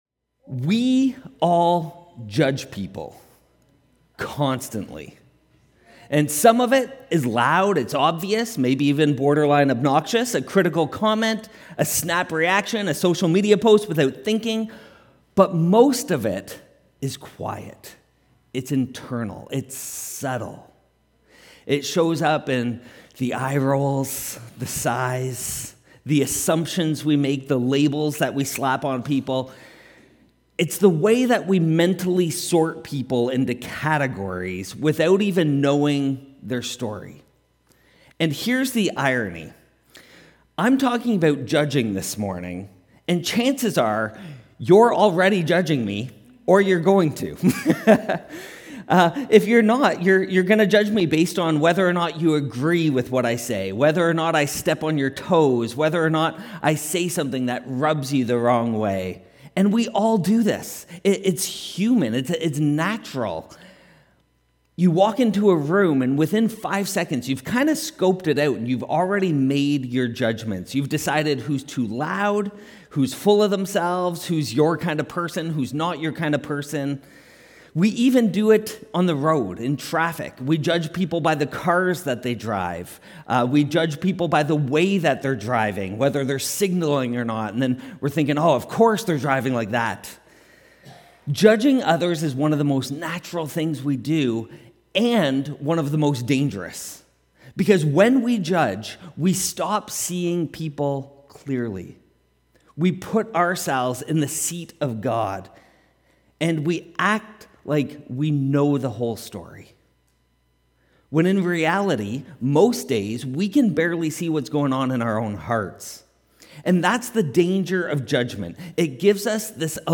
This week, we unpack what Jesus really meant when He said, “Do not judge,” and how we can become people who lead with humility instead of hypocrisy. From road rage to spiritual arrogance — this message goes there.